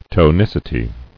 [to·nic·i·ty]